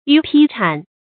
雨僽風僝 注音： ㄧㄩˇ ㄓㄡˋ ㄈㄥ ㄔㄢˊ 讀音讀法： 意思解釋： 見「雨僝風僽」。